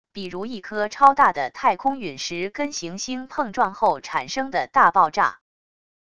比如一颗超大的太空陨石跟行星碰撞后产生的大爆炸wav音频